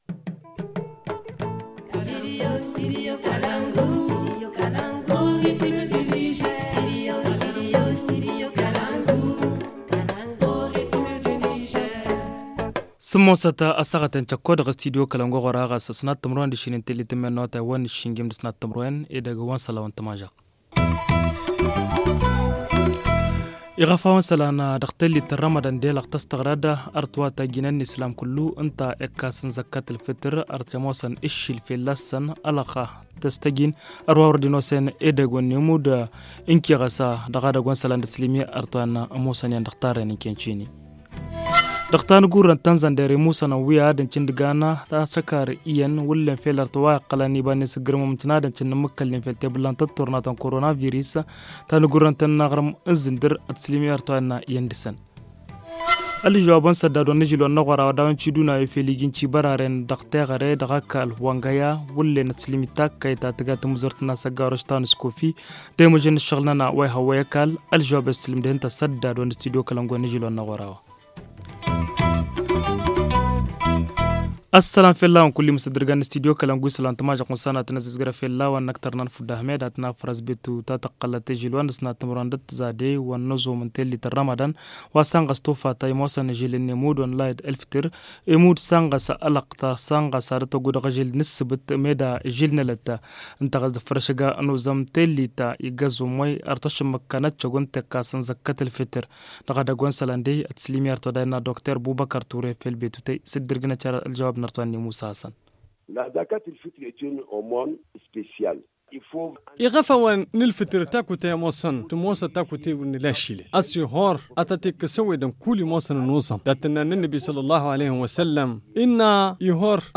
Le journal du 22 mai 2020 - Studio Kalangou - Au rythme du Niger